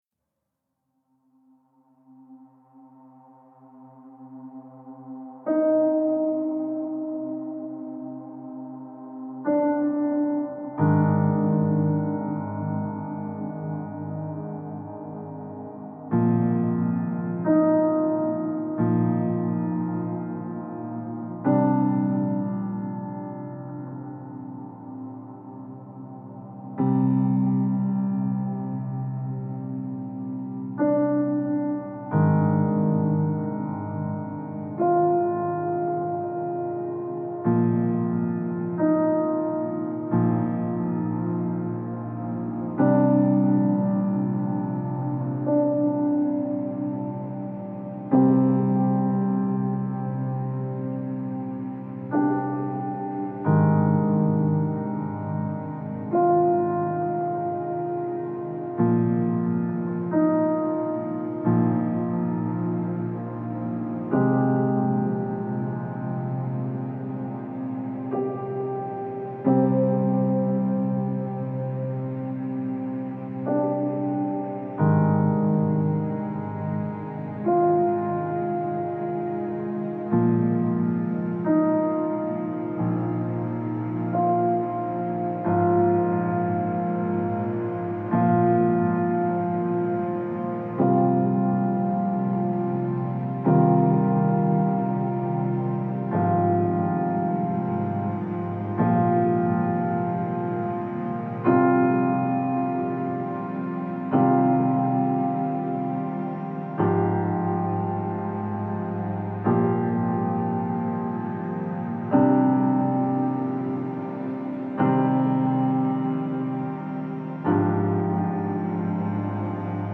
موسیقی کنار تو
الهام‌بخش عمیق و تامل برانگیز موسیقی بی کلام
موسیقی بی کلام زهی